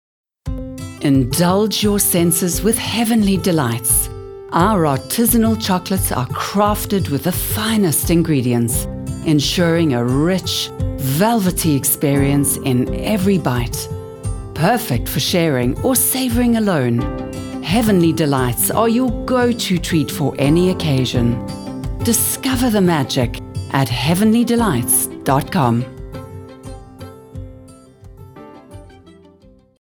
South Africa
authentic, authoritative, confident
45 - Above
Based in the picturesque winelands of the Western Cape, South Africa, I am fortunate to have a fully equipped home studio and can therefore offer clients the facility of remote direction.
My demo reels